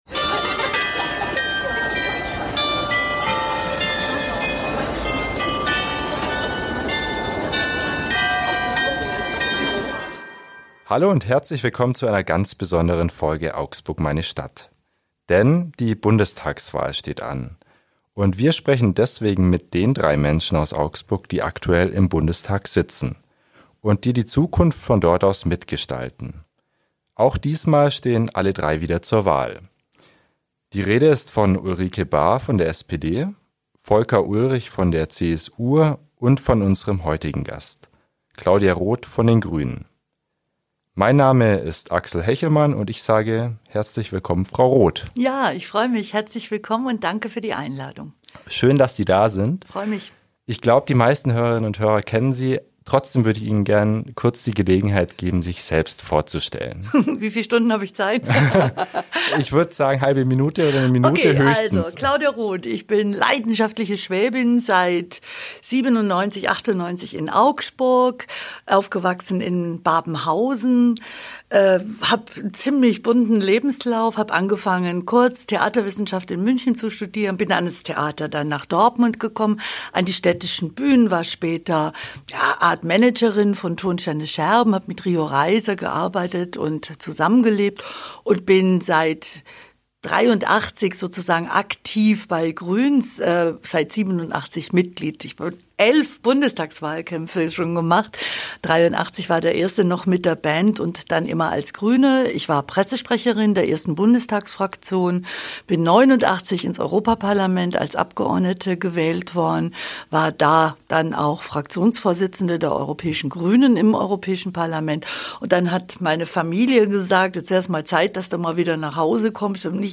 Beschreibung vor 4 Jahren In dieser Folge unseres Podcasts "Augsburg, meine Stadt" spricht die Grünen-Politikerin Roth über politische Ziele, Probleme in Augsburg und ihr Privatleben. Roth setzt sich für Klimaschutz und Kultur ein, außerdem will sie die Demokratie vor Extremisten beschützen.